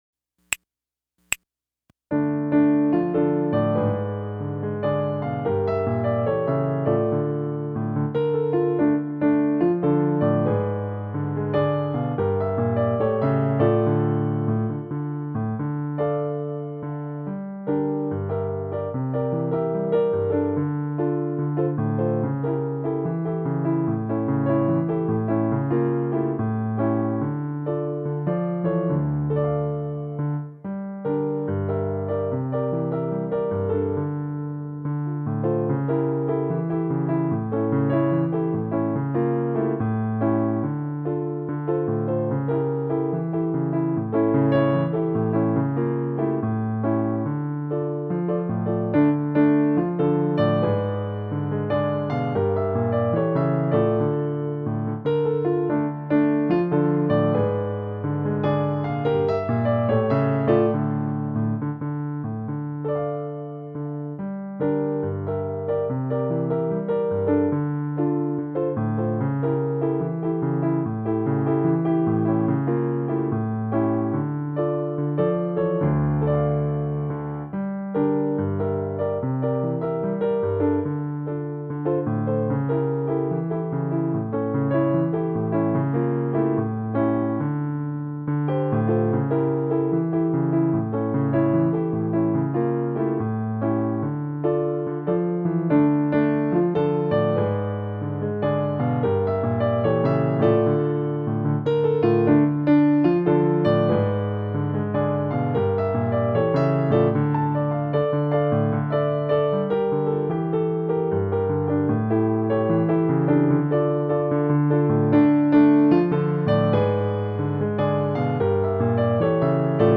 Segue nesse novo projeto, a gravação audiovisual dos arranjo para as 17 músicas do Cordão, para canto coral e piano.
Piano s/ Clique
16-MEU-LEAO-URROU-MIX-1-COM-METRO-WAV-m2-piano-1.mp3